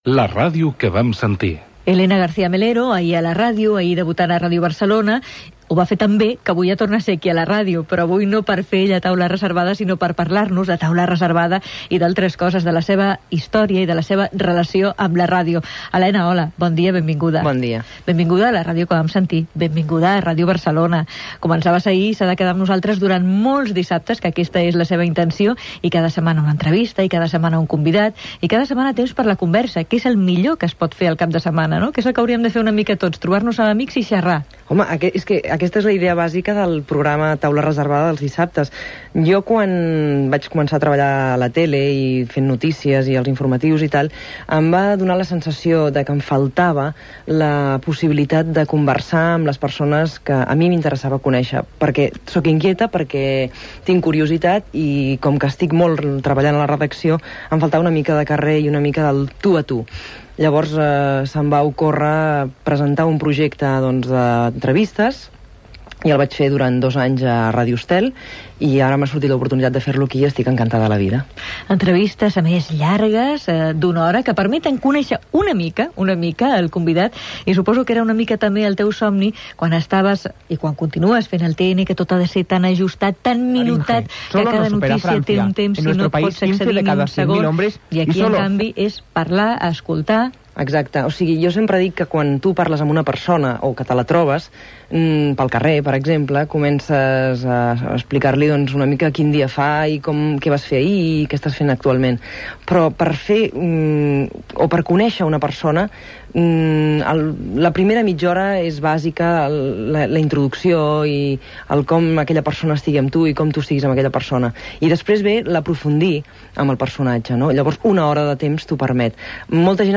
Indicatiu del programa i entrevista
FM